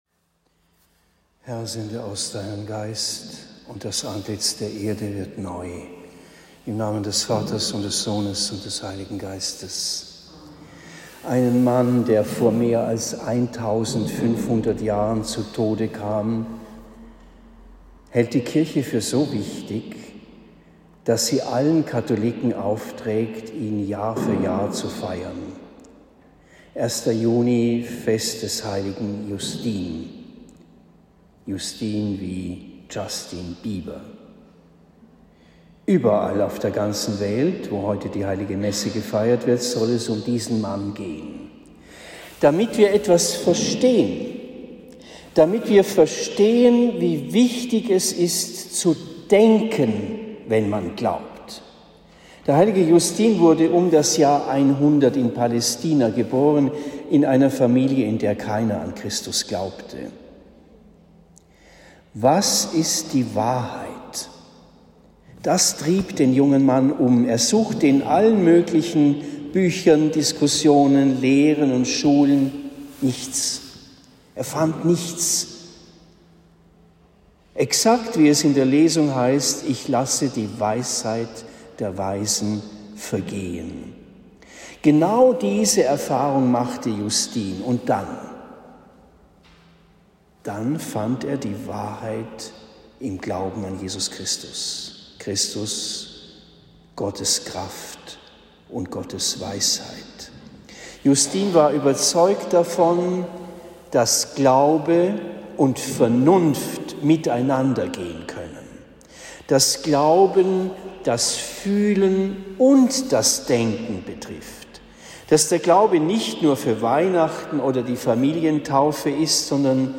Predigt am 01. Juni 2023 in Esselbach